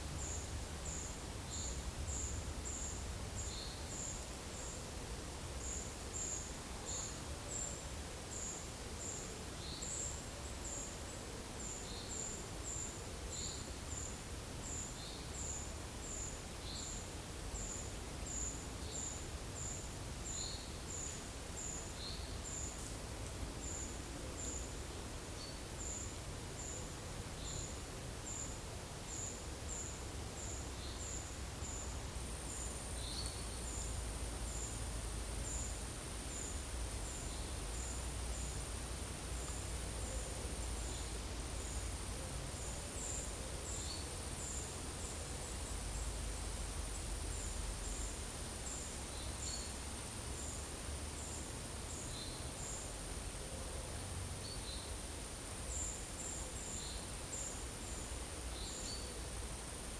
PEPR FORESTT - Monitor PAM
Certhia familiaris
Certhia brachydactyla